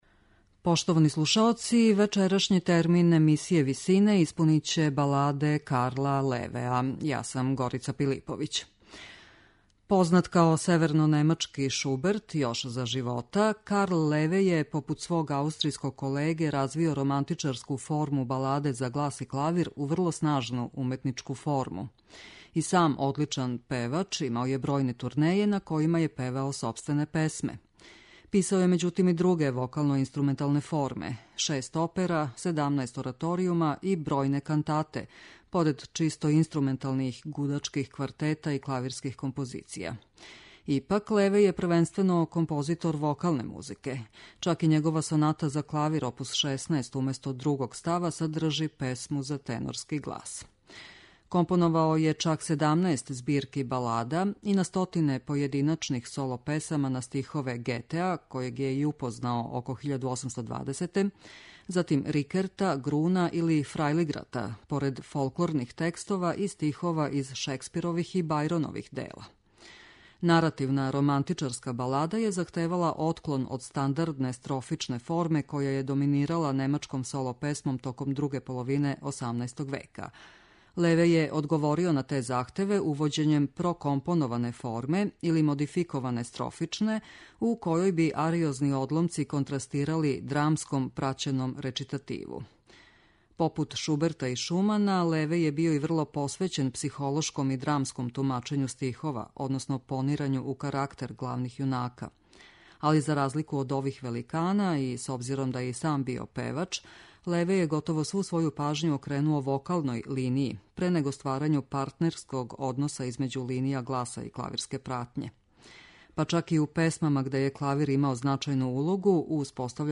Духовна и медитативна музика
Баладе чији је аутор немачки романтичарски композитор Карл Леве (слика на насловној страни), пева чувени баритон Дитрих Фишер-Дискау.